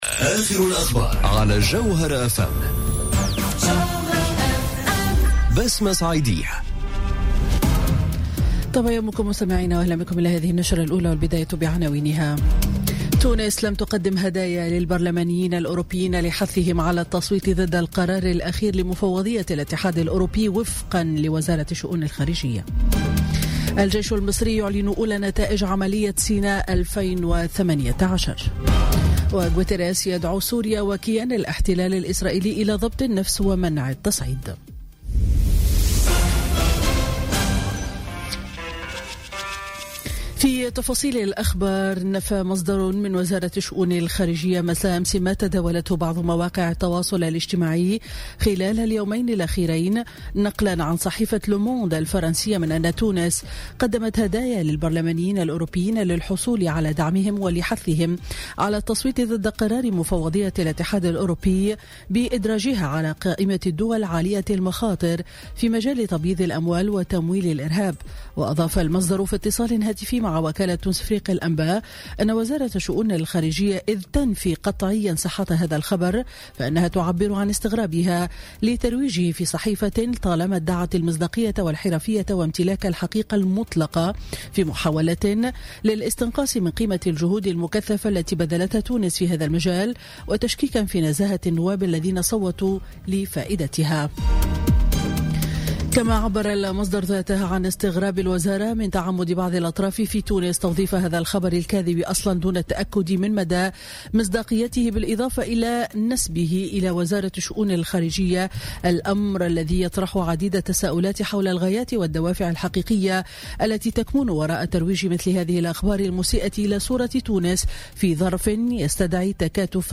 نشرة أخبار السابعة صباحا ليوم الأحد 11 فيفري 2018